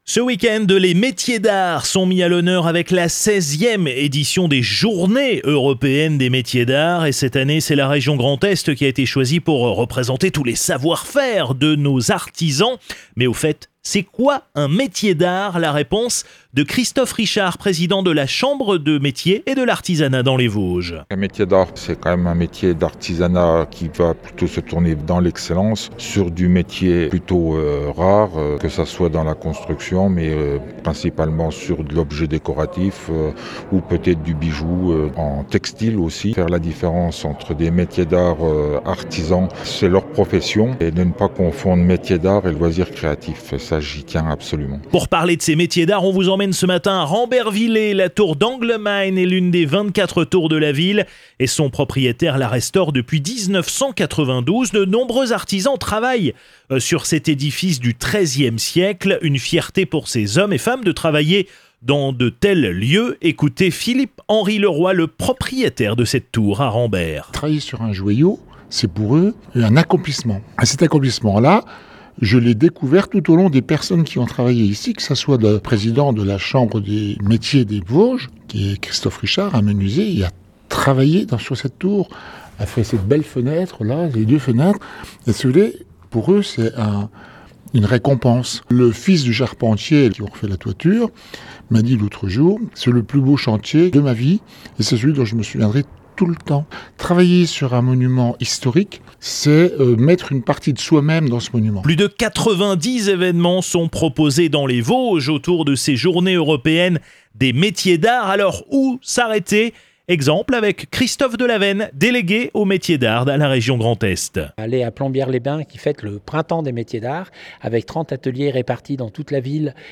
Reportage VOSGES FM